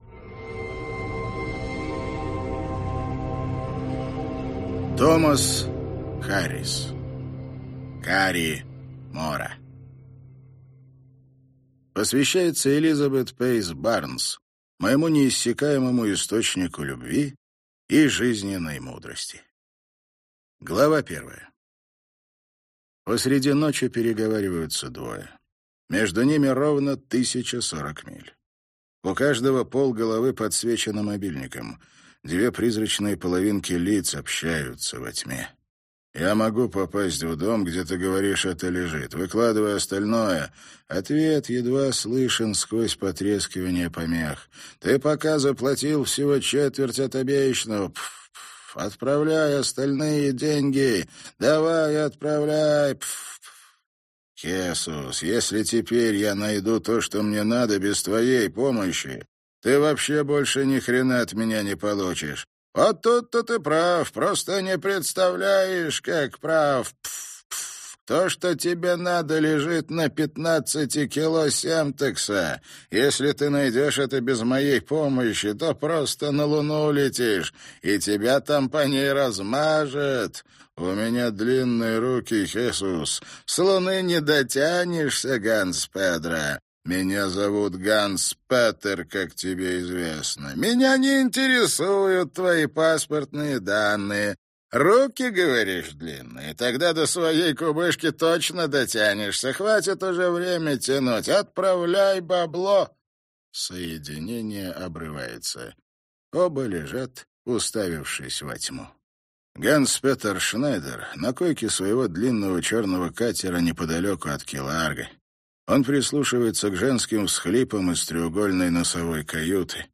Аудиокнига Кари Мора - купить, скачать и слушать онлайн | КнигоПоиск